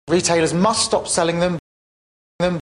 Another word commonly pronounced in this way is the pronoun them. Here are some examples, this time from British TV: